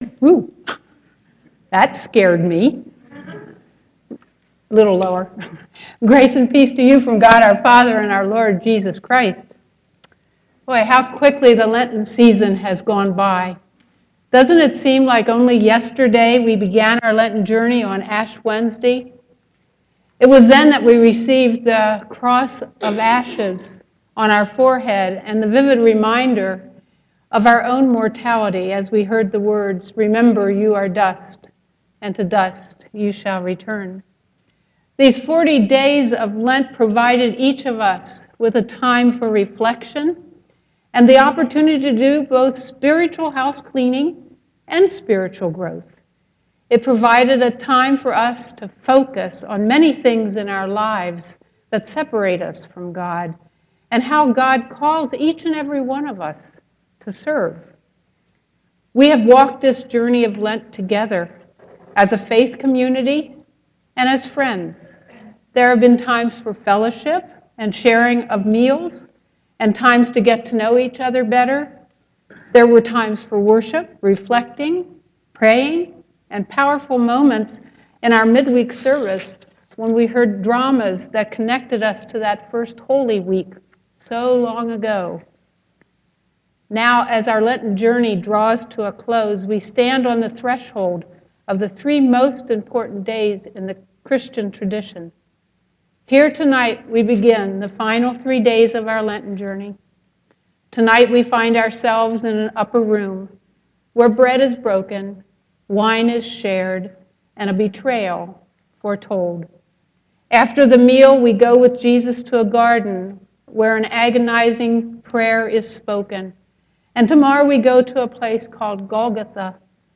Sermon 4.17.2014 Maundy Thursday